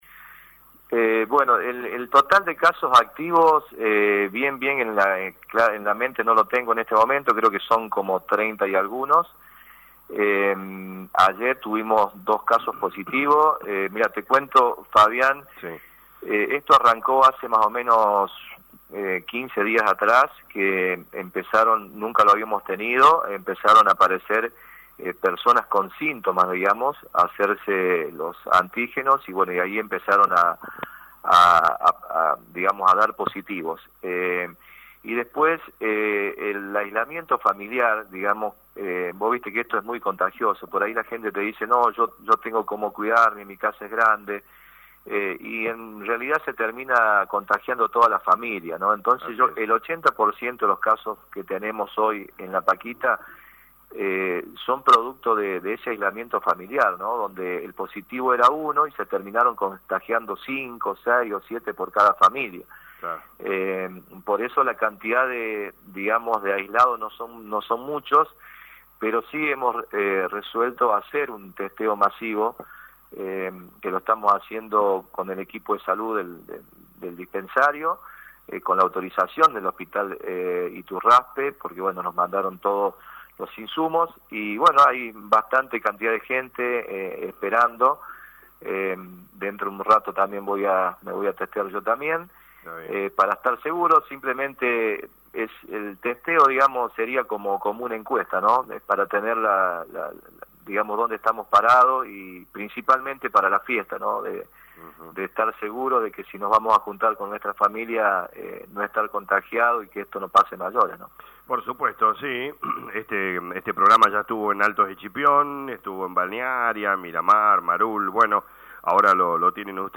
El intendente Rubén Para hablo con LA RADIO 102.9 y destaco que «en los ultimos 15 dias tuvimos un crecimiento de casos y notamos que los contagios se dan entre miembros de una misma familia, ya que al haber un caso y realizando el aislamiento juntos, el resto de la familia se va contagiando por eso pedimos este testeo para saber como estamos».